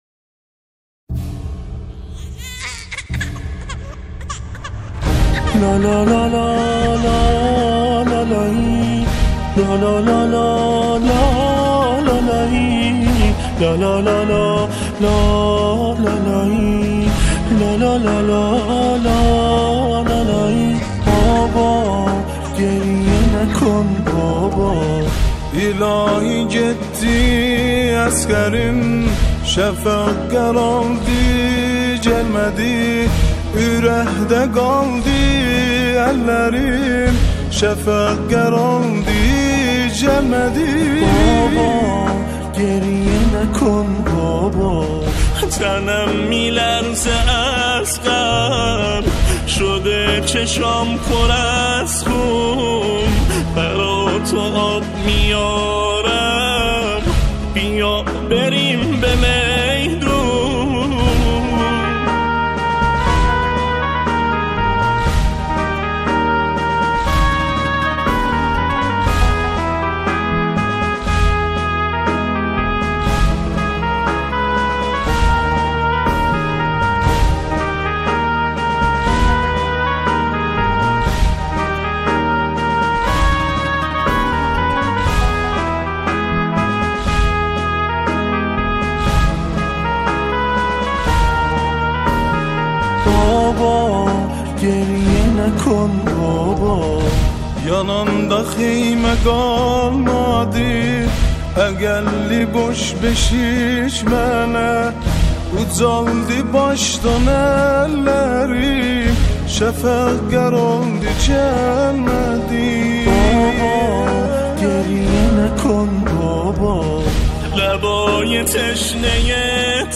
سرودهای حضرت علی اصغر علیه السلام
به صورت یک لالایی